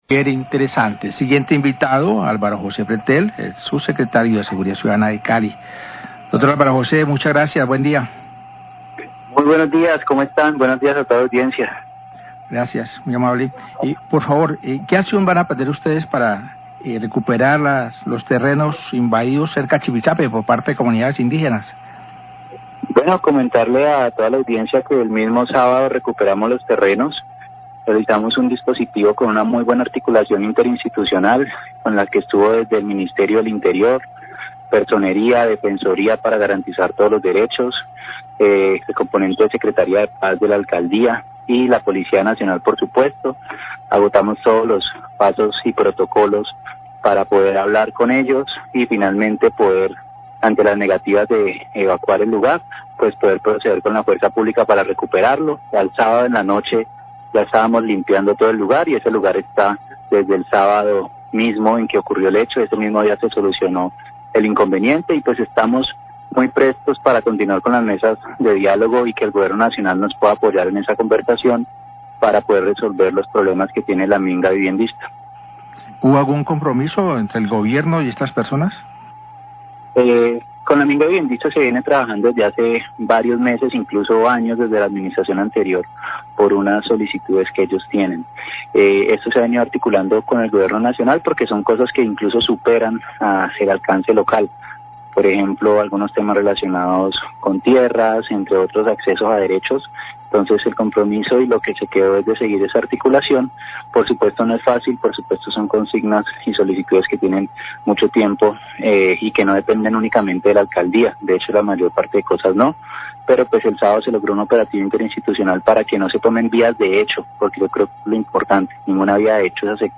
Radio
Subsecretario de Seguridad, Alvaro Pretel , habló acerca de la invasión por parte de comunidades indígenas en el sector de Chipichape el fin de semana. Manifestó que el sábado se gestionó la salida de estas personas y se limpió el predio.